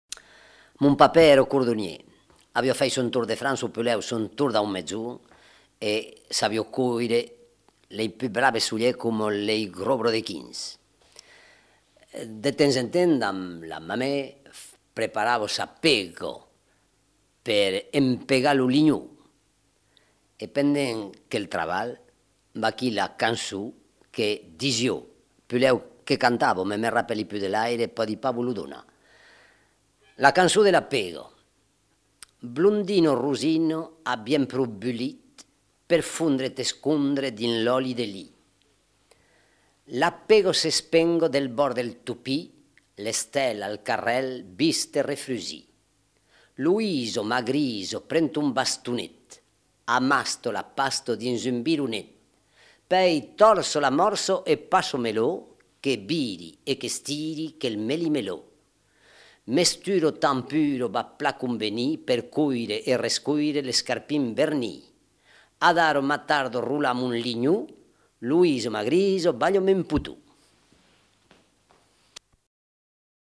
Aire culturelle : Haut-Agenais
Lieu : Lauzun
Genre : chant
Effectif : 1
Type de voix : voix d'homme
Production du son : récité